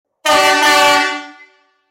Buzinas de Trem
Fabricadas em alumínio, as Buzinas de Trem Di- Fabian aliam design, tecnologia, durabilidade e som diferenciado, garantindo a adequada sinalização sonora e a segurança das pessoas.
• Som agudo e de alta frequência;
• Intensidade sonora 130db;